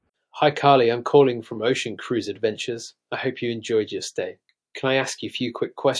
Helios - English (UK) - Male.wav